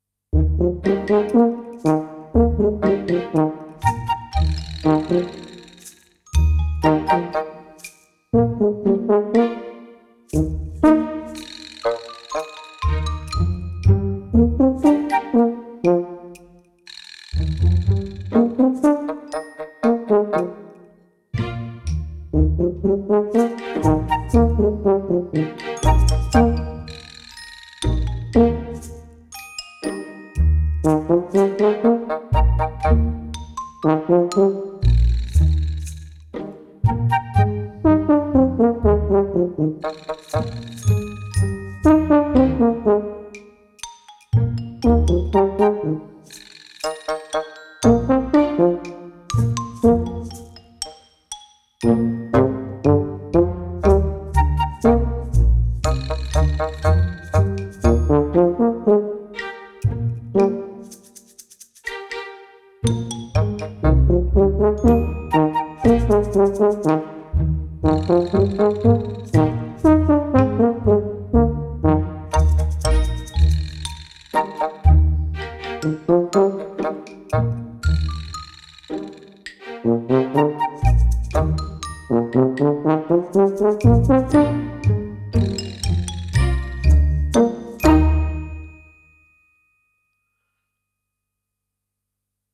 PITCHED PERCUSSION ONLY